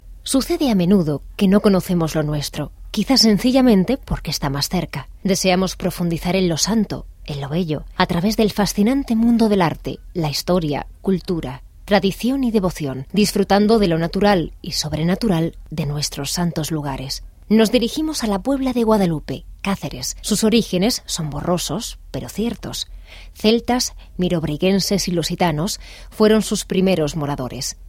Voice over, locutora en castellano, voz femenina
kastilisch
Sprechprobe: Sonstiges (Muttersprache):